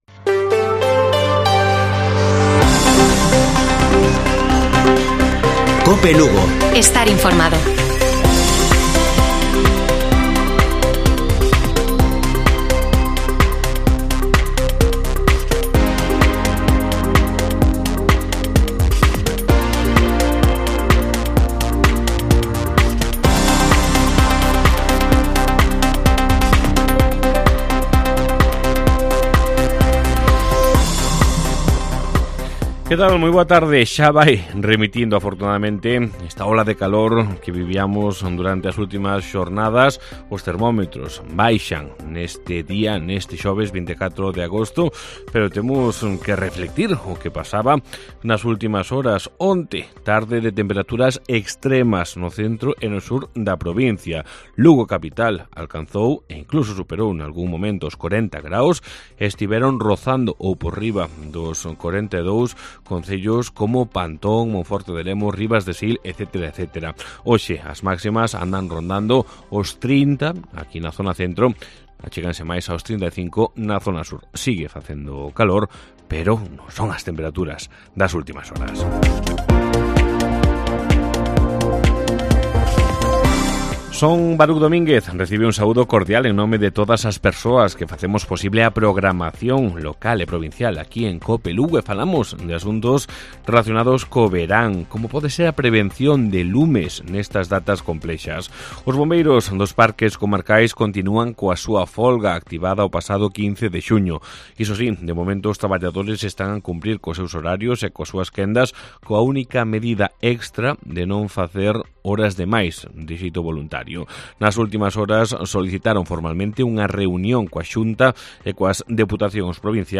Informativo Mediodía de Cope Lugo. 24 de agosto. 14:20 horas